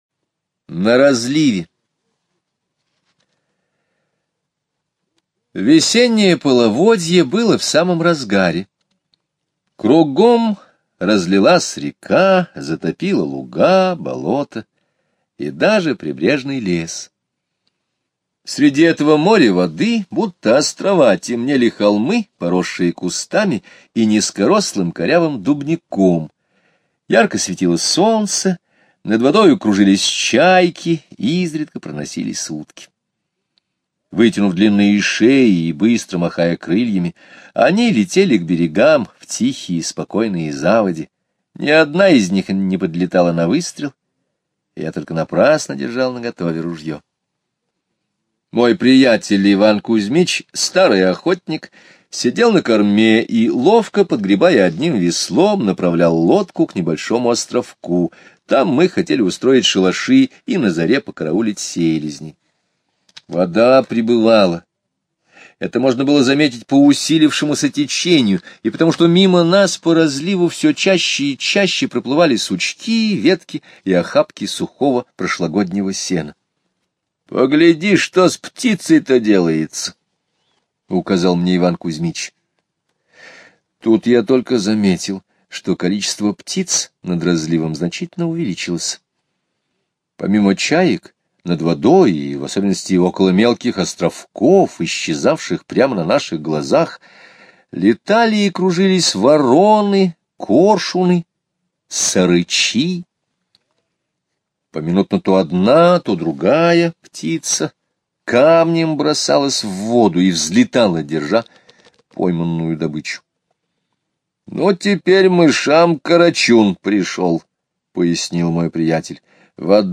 Слушайте аудио рассказ "На разливе" Скребицкого Г. онлайн на сайте Мишкины книжки. Автор во время весеннего разлива с другом плавали на лодке и наблюдали за животными вокруг. skip_previous play_arrow pause skip_next ...